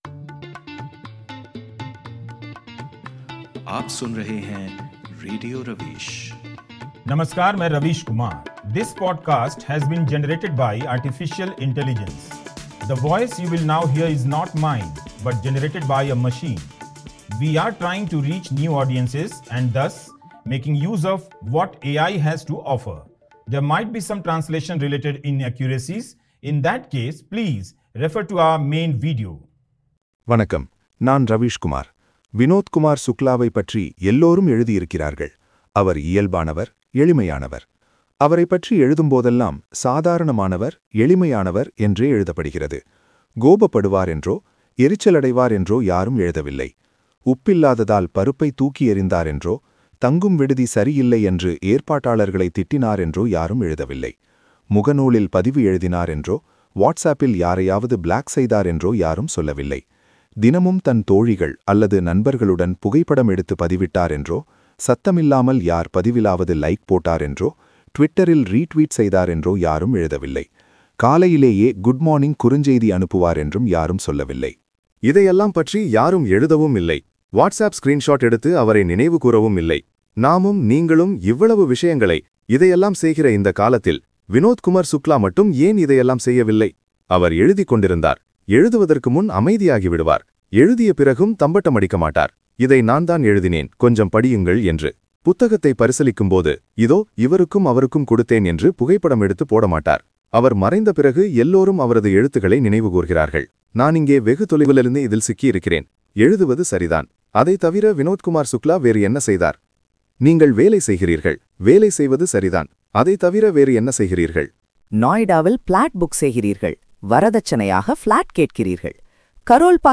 December 24, 2025, 12:49PM புகழ்பெற்ற எழுத்தாளர் வினோத் குமார் சுக்லாவிற்குச் சமர்ப்பிக்கப்படும் இந்த நெகிழ்ச்சியான புகழாரத்தில், ரவிஷ் குமார் அவரது ஆழ்ந்த எளிமையைக் குறித்துப் பேசுகிறார். நவீன சமூக ஊடகங்கள் மற்றும் அரசியலின் ஆரவாரமான, பாவனை மிகுந்த கலாச்சாரத்திற்கு முற்றிலும் மாறுபட்ட, வினோத் குமார் சுக்லாவின் அமைதியான மற்றும் பகட்டற்ற வாழ்க்கையை அவர் இதில் ஒப்பிடுகிறார்.